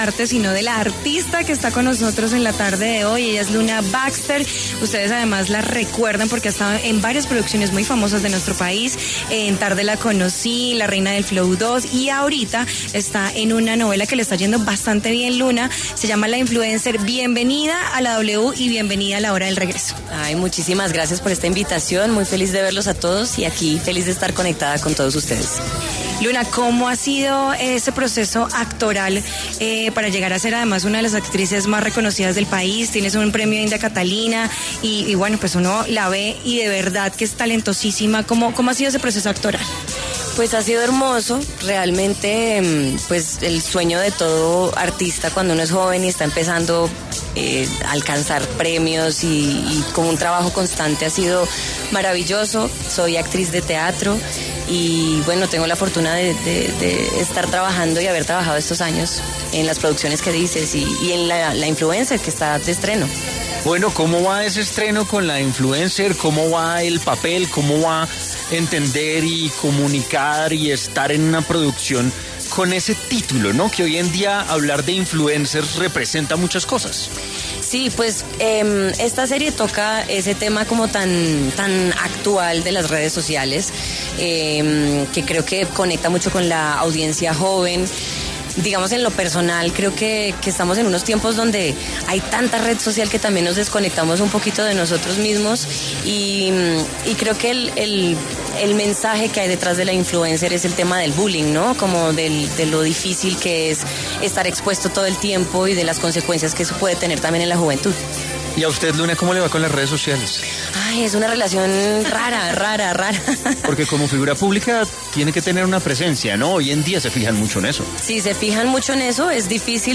Luna Baxter, actriz y cantante, pasó por los micrófonos de La Hora del Regreso de W Radio y conversó sobre su más reciente proyecto actoral como ‘Abril’ en ‘La Influencer’.